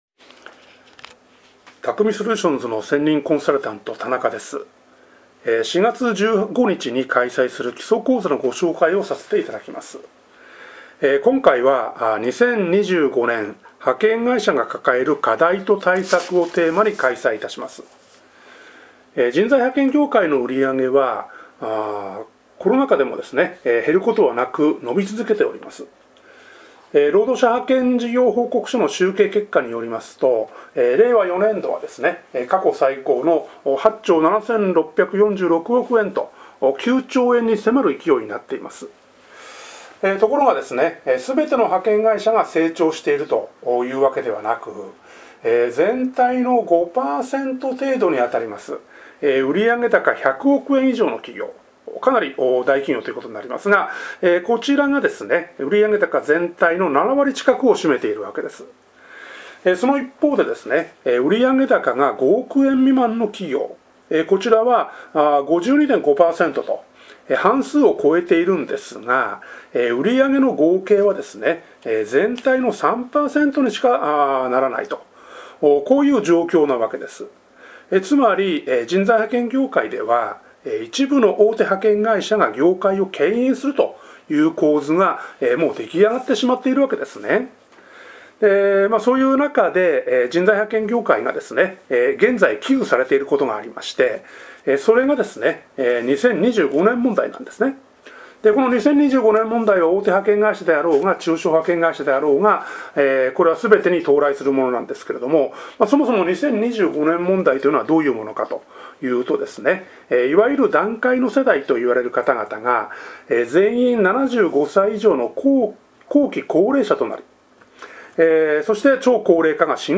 音声による講座内容の詳細
音声解説